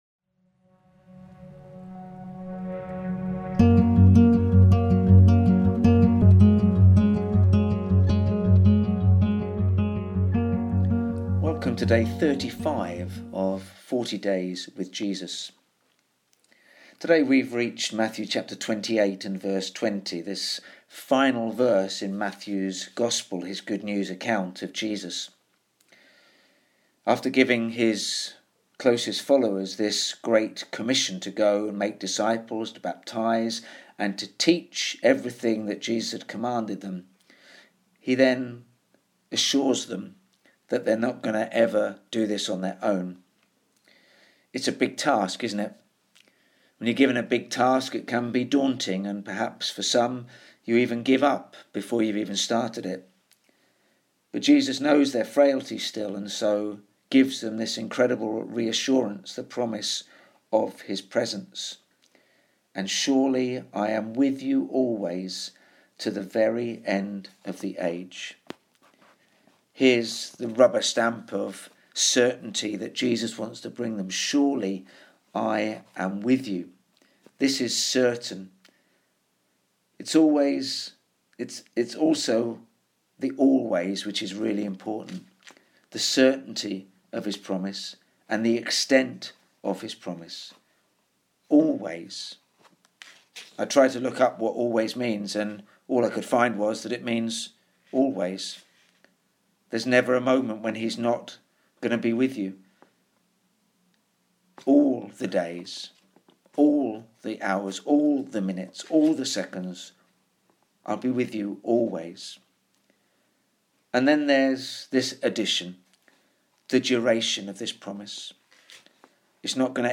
Bible Text: Matthew 28:20 | Preacher
We will be posting short, daily reflections as we journey through the encounters people had with the risen Jesus.